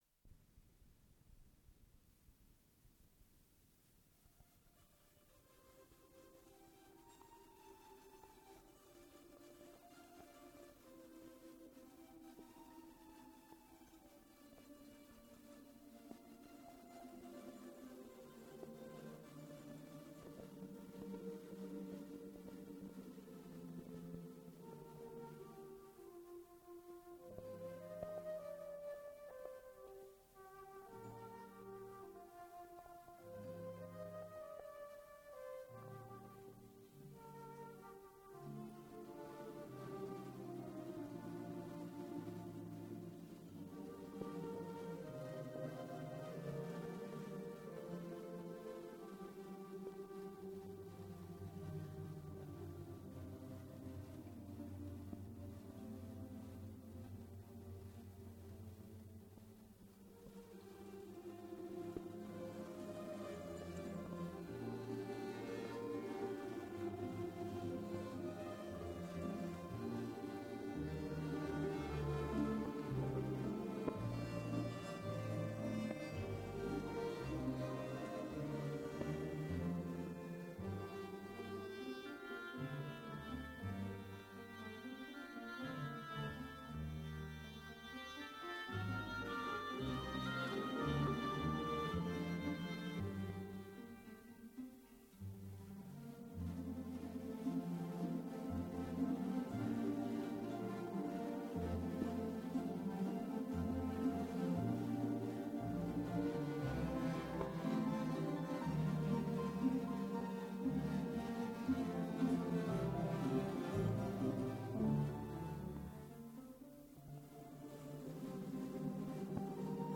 Дубль моно.